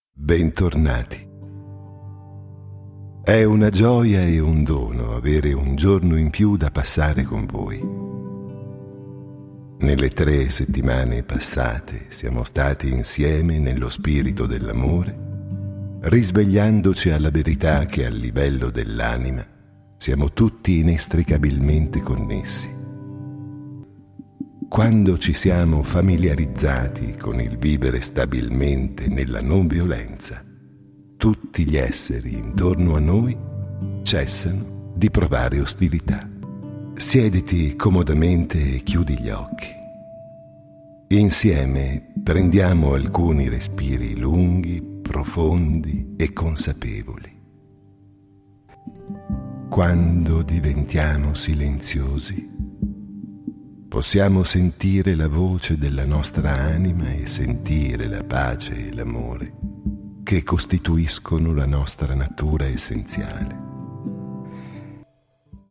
legge le meditazioni di Deepak Chopra.